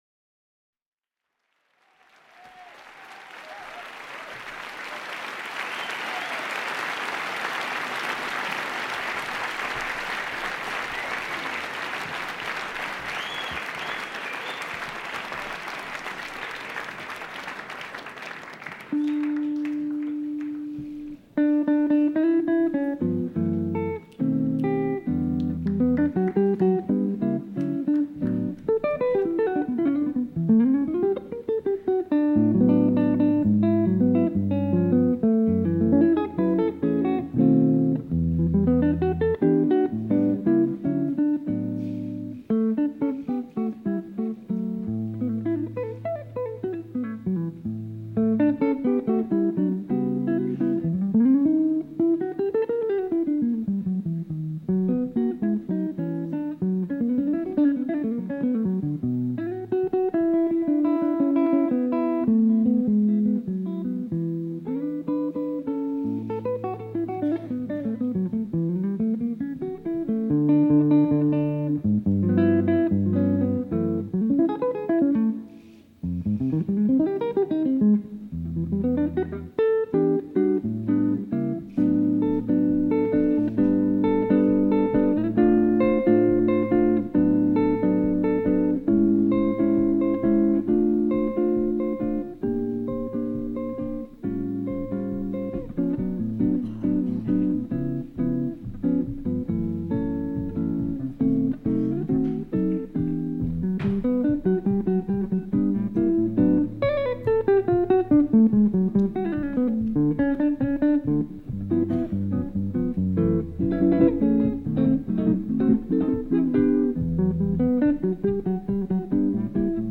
ジャンル：JAZZ-ALL
LP
店頭で録音した音源の為、多少の外部音や音質の悪さはございますが、サンプルとしてご視聴ください。
その彼の真骨頂であるソロギターによる1975年ライブ録音。